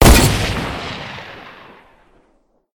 mgun2.ogg